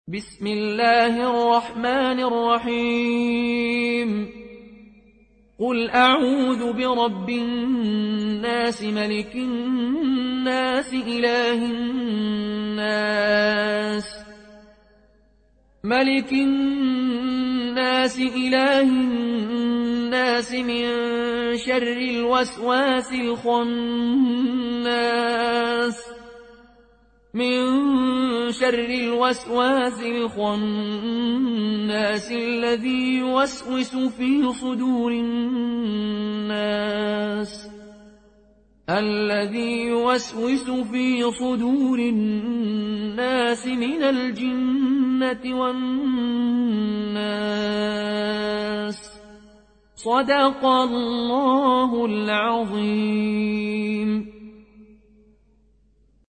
Riwayat Qaloon an Nafi